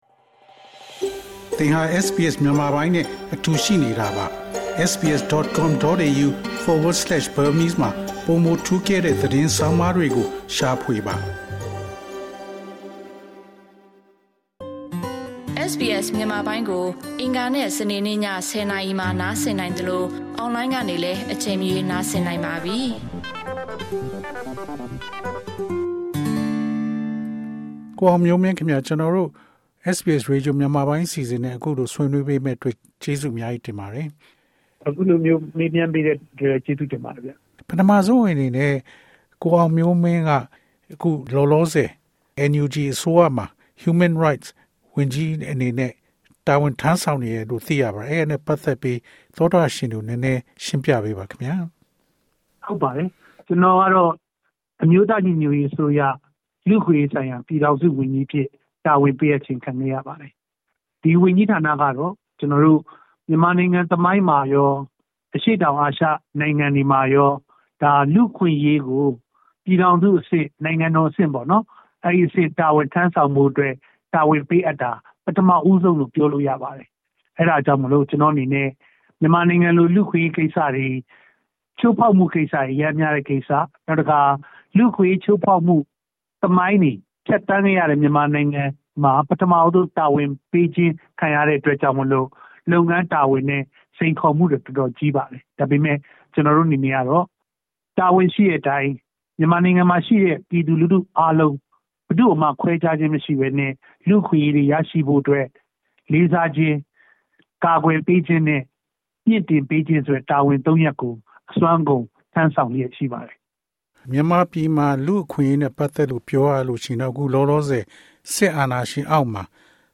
NUG လူ့အခွင့်အရေးဝန်ကြီး ဦးအောင်မျိုးမင်း တွေ့ဆုံမေးမြန်းခန်း အပိုင်း ၁